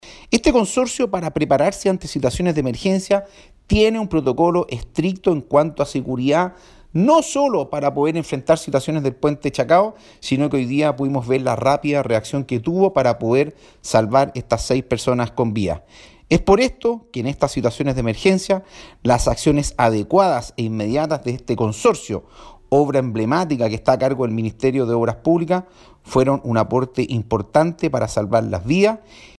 Destacó el seremi la existencia de un completo programa de emergencia que mantiene el Consorcio Puente Chacao para casos como el ocurrido el día sábado.